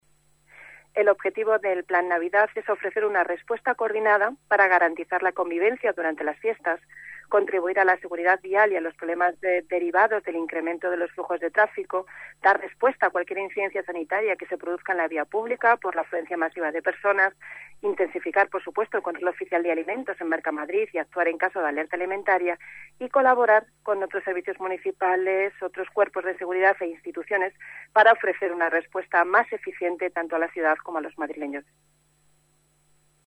Nueva ventana:Declaraciones de Fátima Núñez, concejala delegada del Area de Seguridad y Emergencias